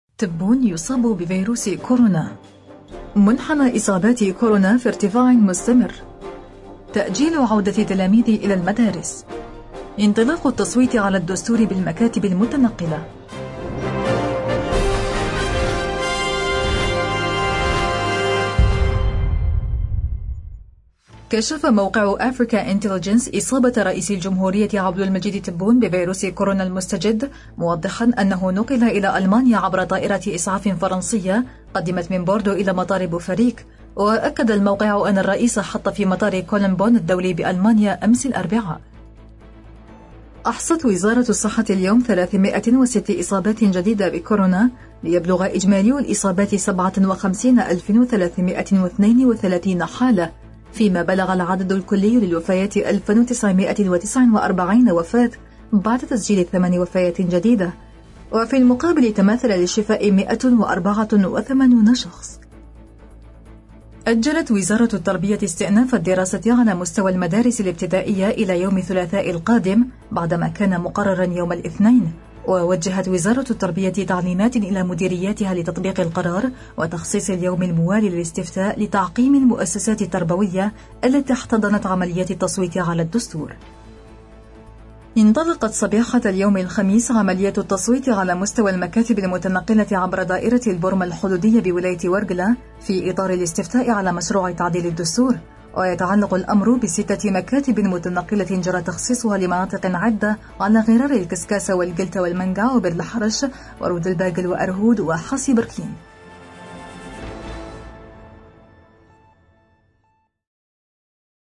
النشرة اليومية: تأكيد إصابة تبون بكورونا – أوراس